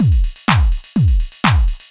Techno Beat_125.wav